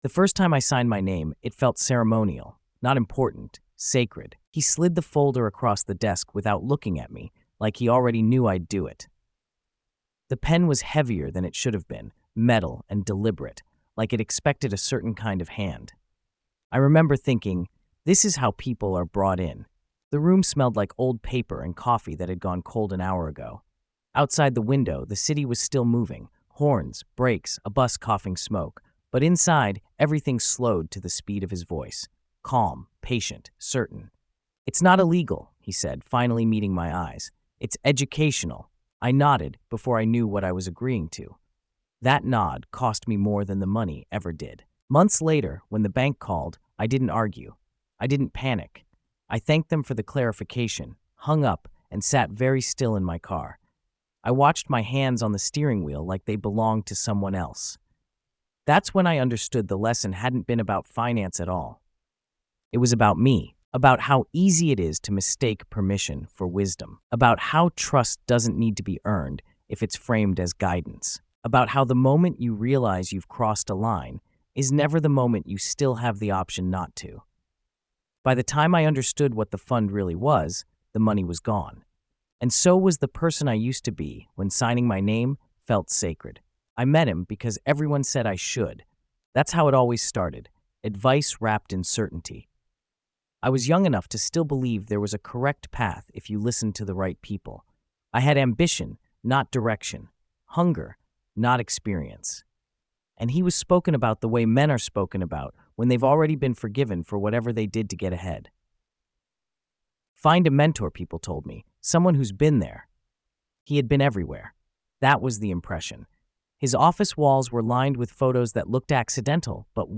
Told in first-person, The Mentor’s Fund traces the quiet psychology of consent: how admiration becomes dependence, how ethical lines blur when framed as education, and how authority doesn’t need to coerce when it can convince.